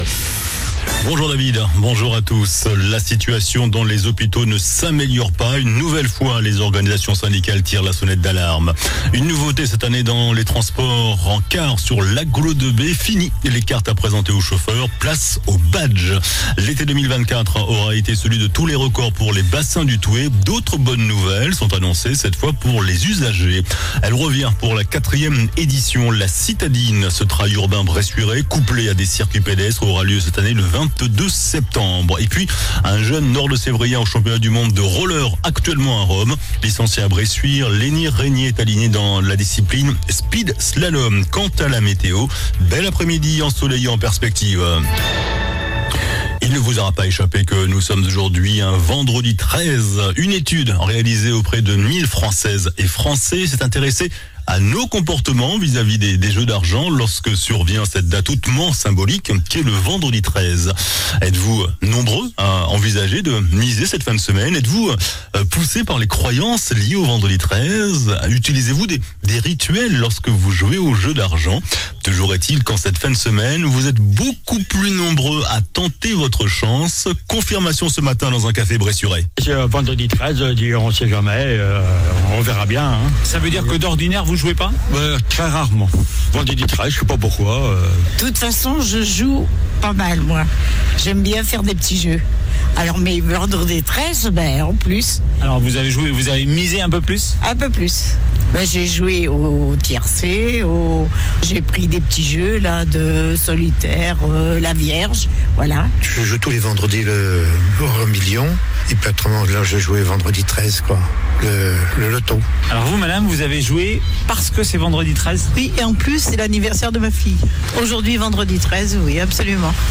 JOURNAL DU VENDREDI 13 SEPTEMBRE ( MIDI )